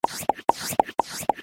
جلوه های صوتی
دانلود صدای آب 56 از ساعد نیوز با لینک مستقیم و کیفیت بالا